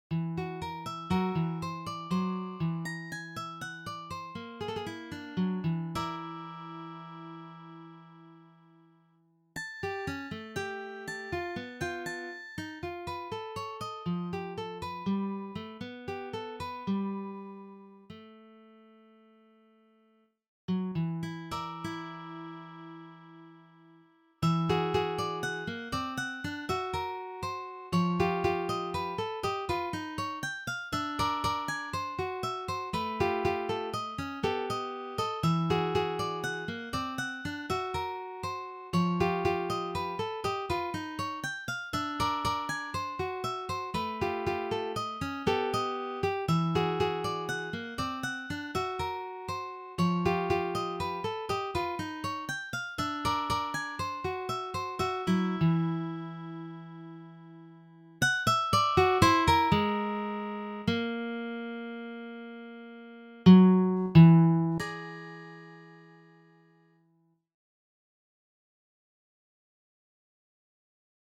Yet, they are quite easy on the listener.